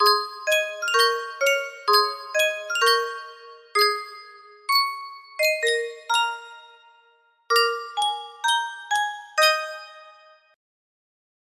Sankyo Music Box - Hail to the Orange RJN music box melody
Full range 60